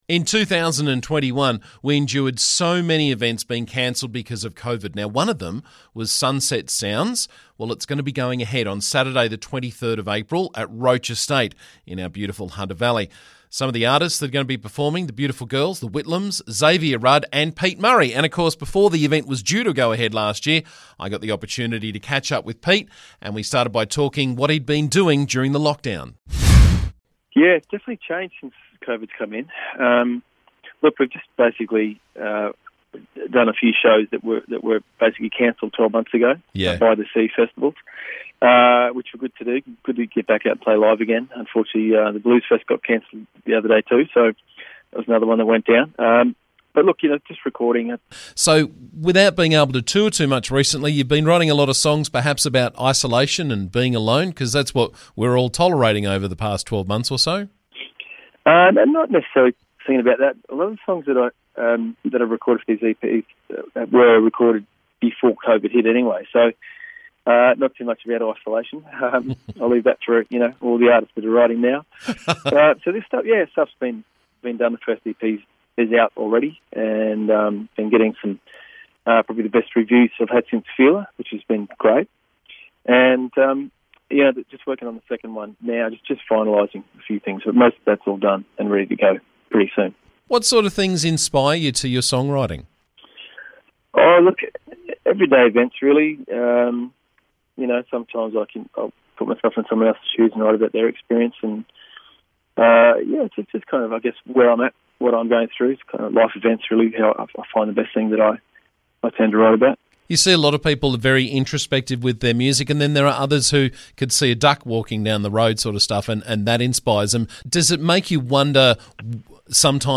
Sunset Sounds was postponed from November to this April so here's my interview with Pete Murray as we look forward to another great event in the Hunter.